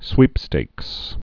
(swēpstāks)